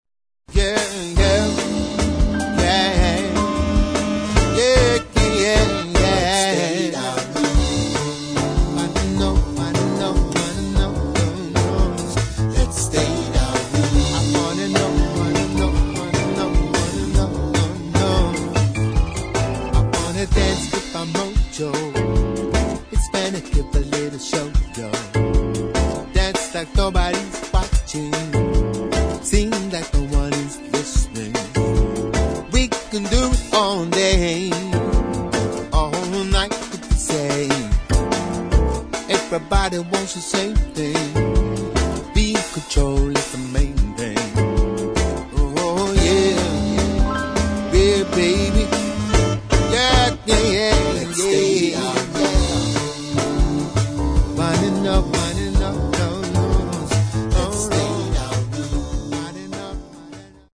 [ JAZZ / SOUL ]